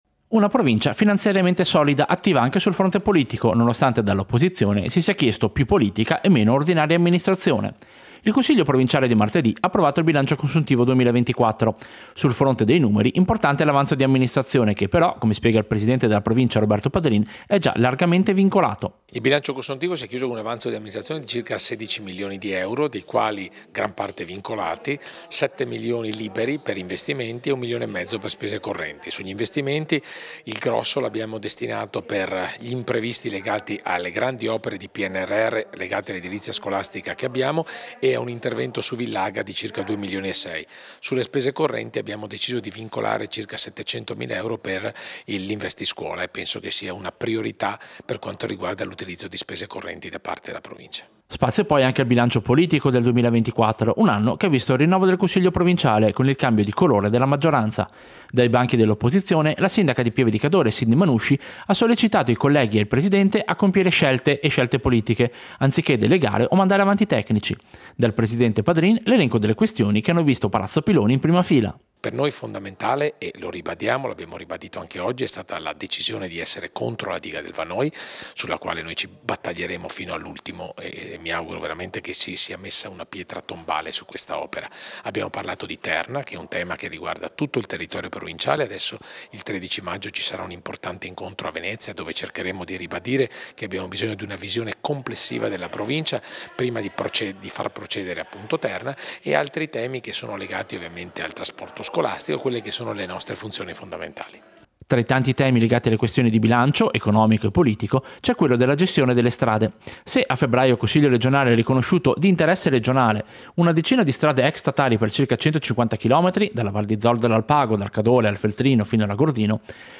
Servizio-Consiglio-provinciale-bilancio-e-strade.mp3